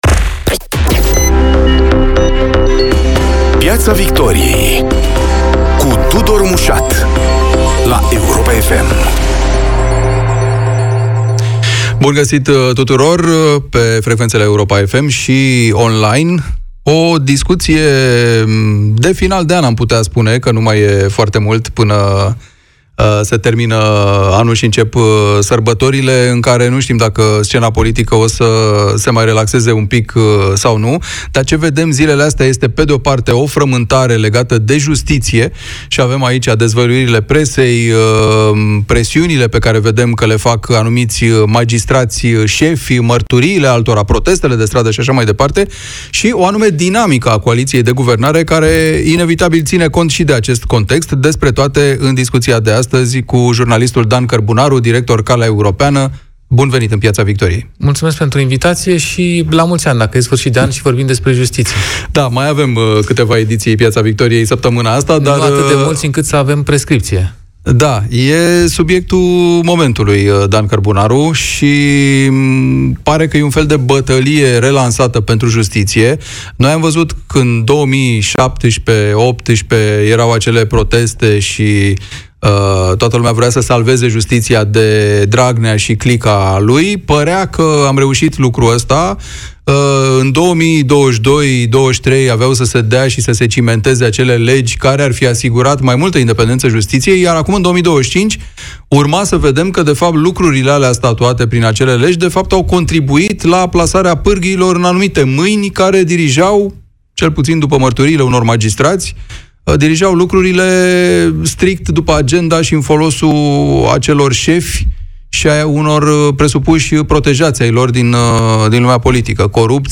Schengen, un pod tot mai îndepărtat? Invitat este europarlamentarul REPER, Dragoș Tudorache | VIDEO